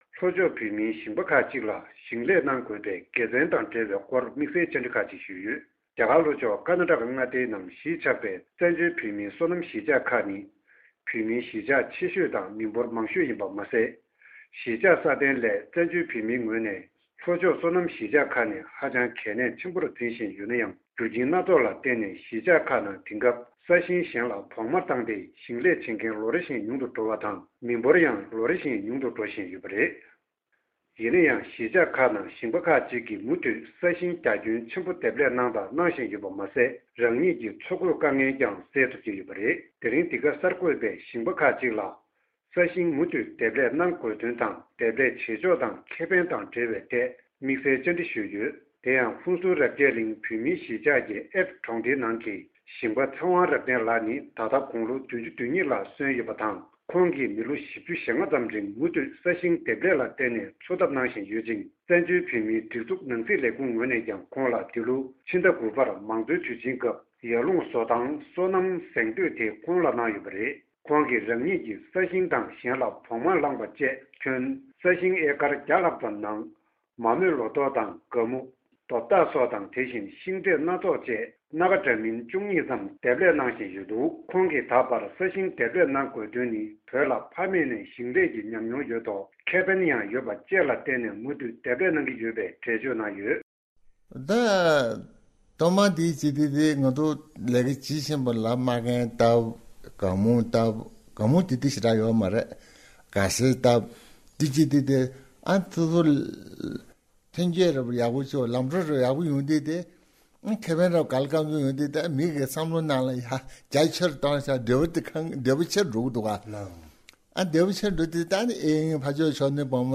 རྒྱ་གར་ལྷོ་ཕྱོགས་ཀྱི་ཧོན་སུར་བོད་མིའི་གཞིས་ཆགས་སུ་ལོ་༤༥་ལ་ས་ཞིང་འདེབས་ལས་བྱས་ཏེ་འཚོ་བར་རོལ་བཞིན་པའི་ཞིང་པ་ཁག་ཅིག
བཅར་འདྲི